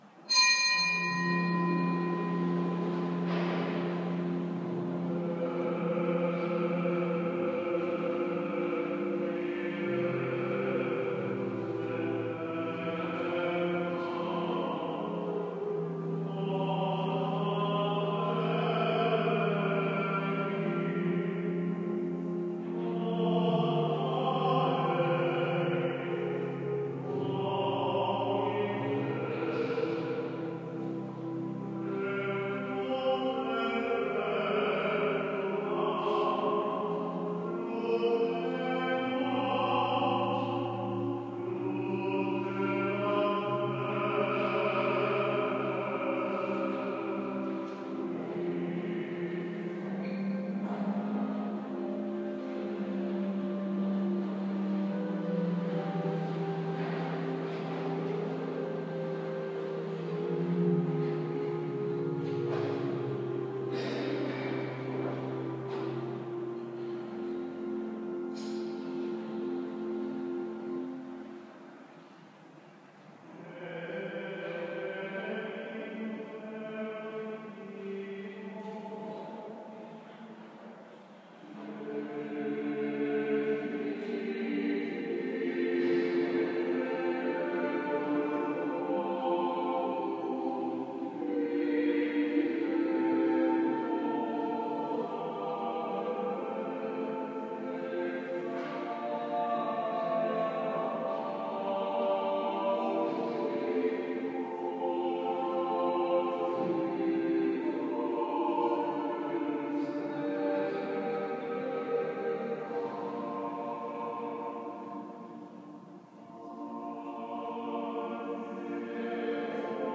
Requiem Mass [AUDIO]
Audio of the Solemn High Mass on the Feast of the Commemoration of All the Faithful Departed at Santissima Trinita dei Pellegrini, Rome: